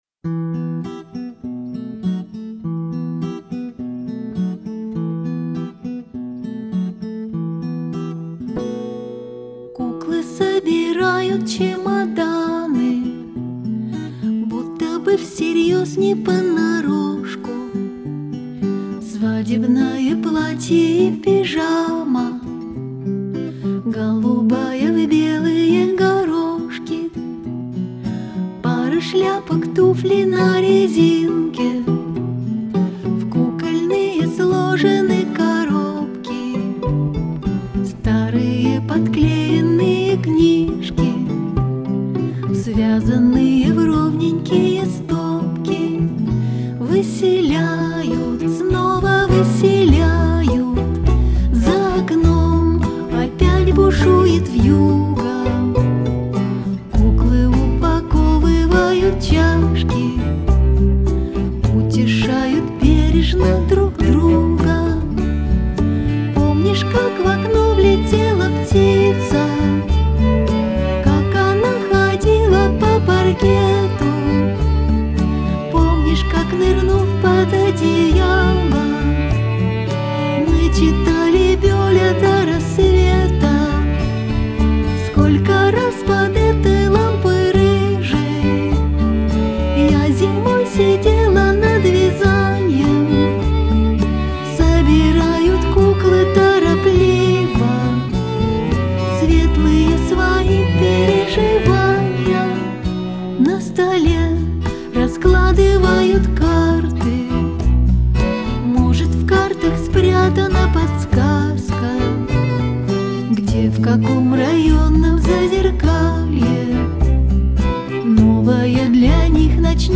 играющая в стиле "Сенти-Ментальный рок".
гитары, клавишные, перкуссия, сэмплы
скрипка
бас-гитара
виолончель